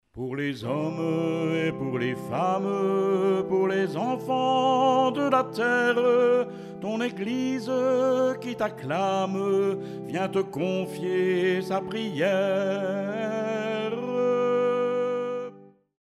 les paroles (Paroles disponibles) , éventuellement les bandes son pupitres (en ligne au fur et à mesure)